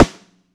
gretsch damped sn ff.wav